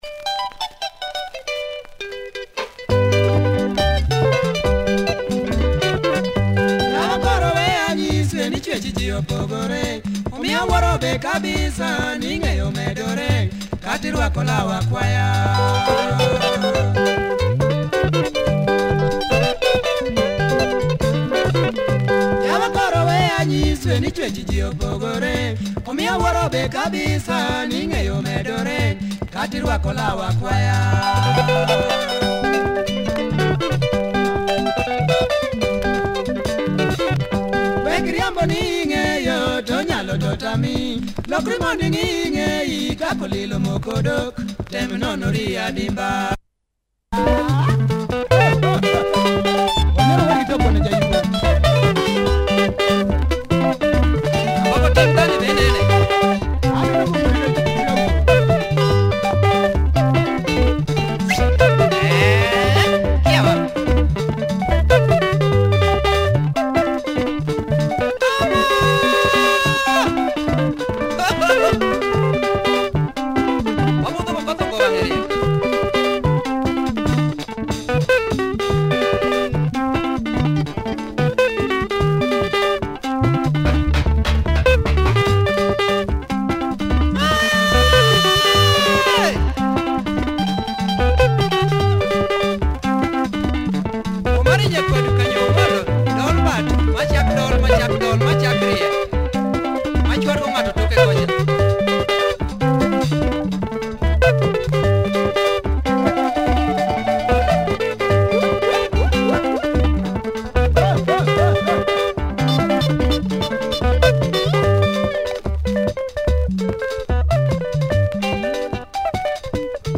Tight LUO benga